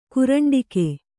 ♪ kuraṇḍike